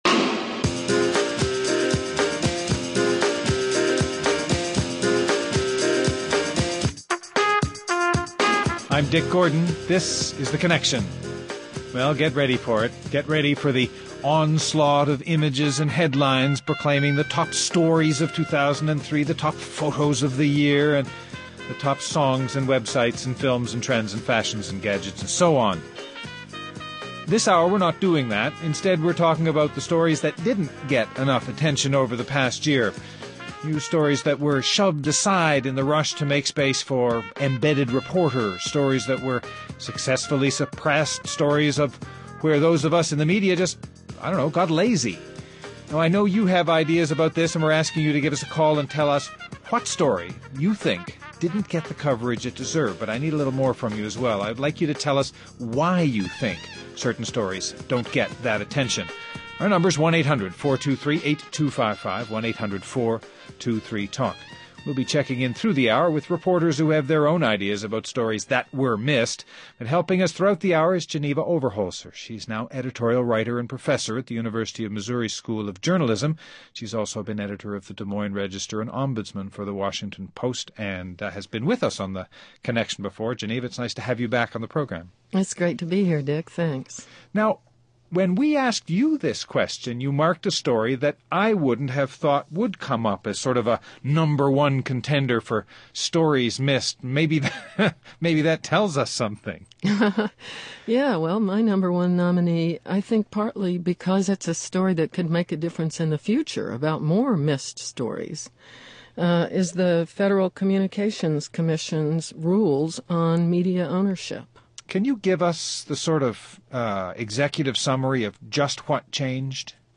So this hour, we are talking to a few journalists, and you, about the stories we missed last year. Reviewing the archives, the stories that got away in 2003.